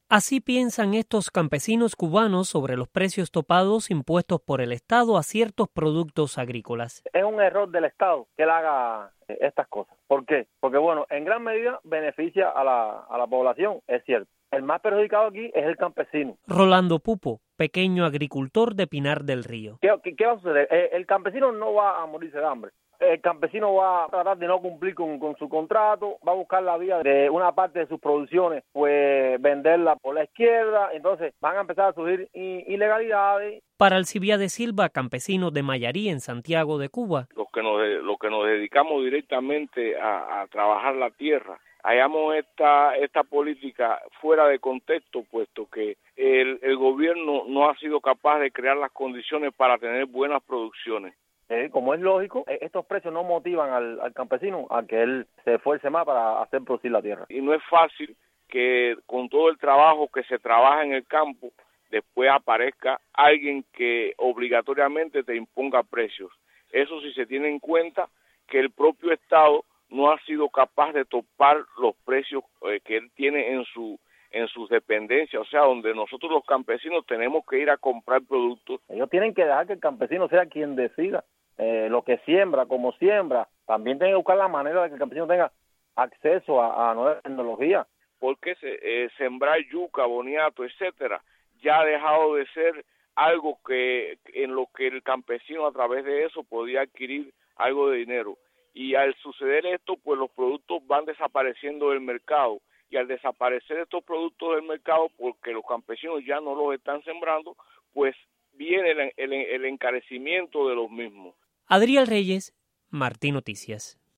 Dos campesinos opinan sobre la actual política de precios